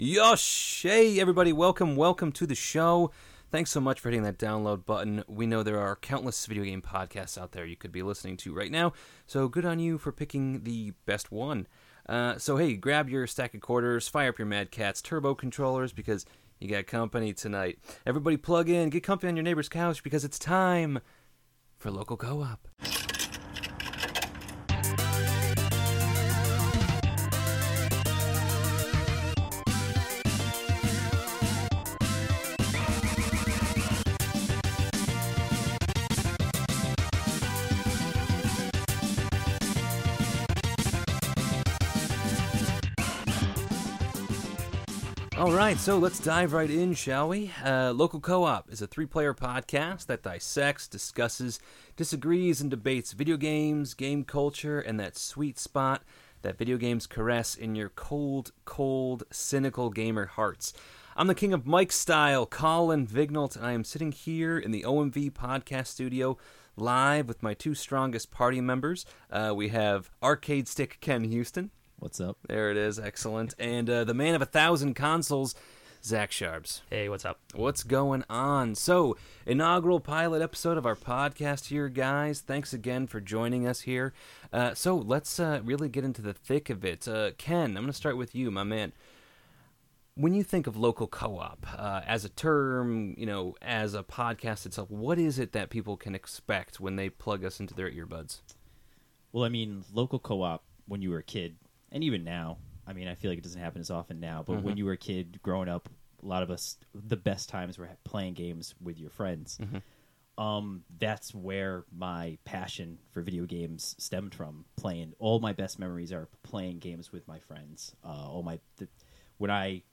Three friends join heads to dissect the reasons we love these games, whether it’s the characters, the story, the game itself, or the camaraderie that comes with sitting on the couch next to your best friends as you utterly decimate them one after the other in Smash Brothers (no items.)